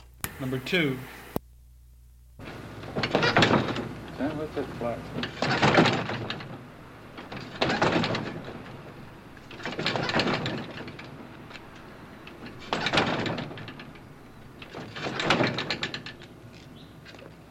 老式汽车 " G1301汽车启动器
描述：汽车引擎奋力开始几次并最终开始。再次停止并开始。轮胎尖叫。安静。 这些是20世纪30年代和20世纪30年代原始硝酸盐光学好莱坞声音效果的高质量副本。 40年代，在20世纪70年代早期转移到全轨磁带。我已将它们数字化以便保存，但它们尚未恢复并且有一些噪音。
Tag: 汽车 运输 光学 经典